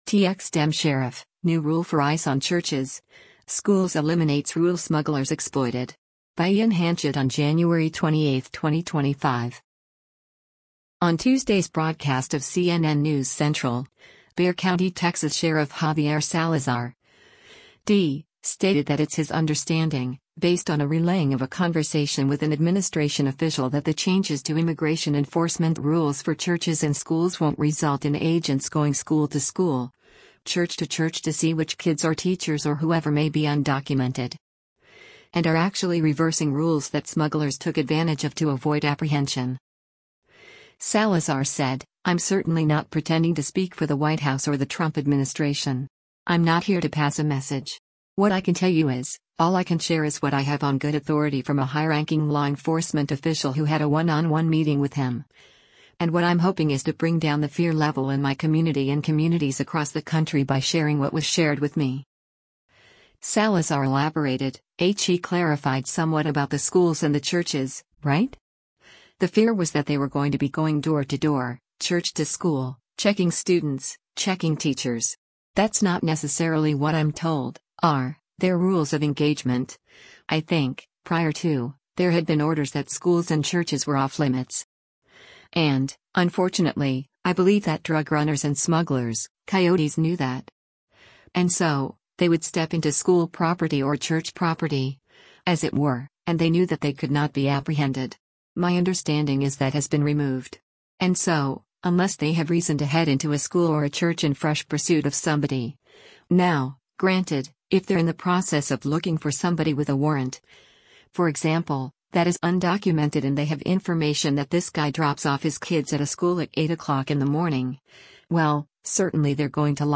On Tuesday’s broadcast of “CNN News Central,” Bexar County, TX Sheriff Javier Salazar (D) stated that it’s his understanding, based on a relaying of a conversation with an administration official that the changes to immigration enforcement rules for churches and schools won’t result in agents going “school to school, church to church to see which kids or teachers or whoever may be undocumented.”